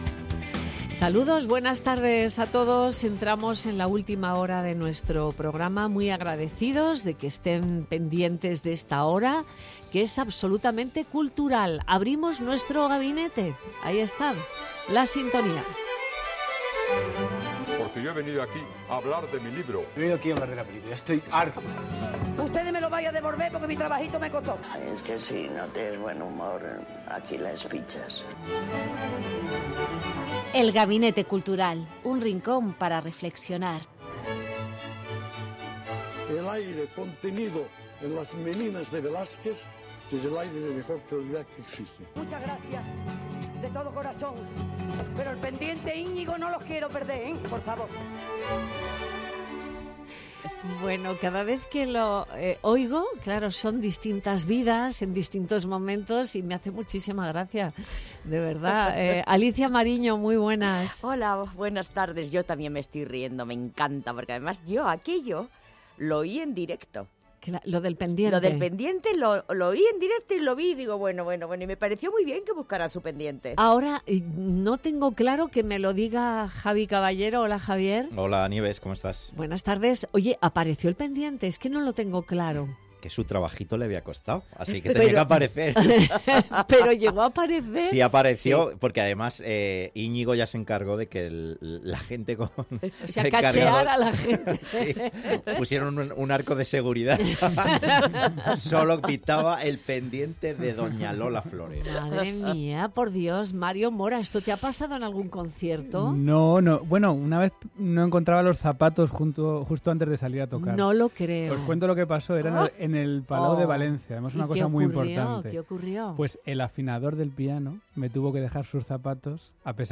Nieves Herrero se pone al frente de un equipo de periodistas y colaboradores para tomarle el pulso a las tardes. Tres horas de radio donde todo tiene cabida: análisis de la actualidad, cultura, ciencia, economía... Te contamos todo lo que puede preocupar a los madrileños.